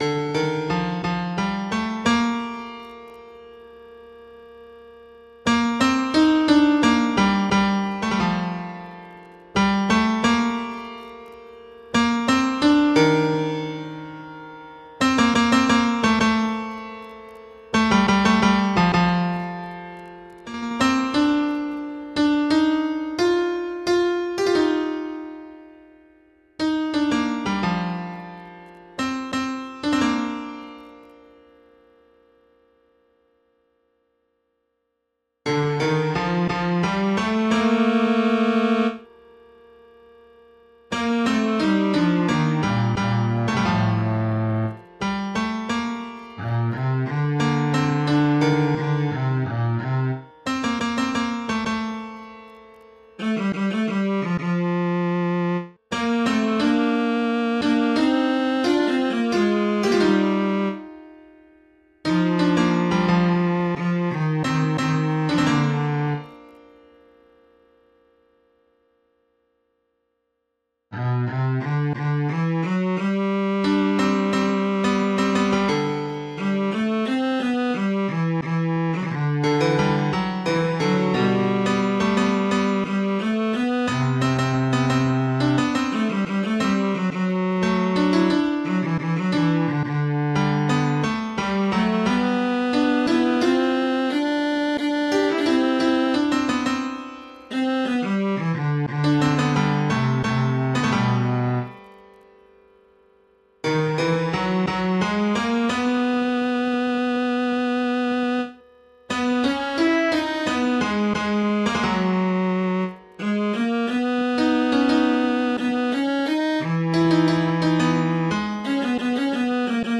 婚禮獻詩檔案
生之頌(兩部鋼琴版):